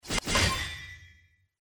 UI_Trophy.mp3